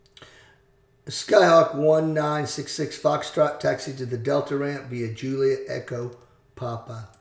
Aviation Radio Calls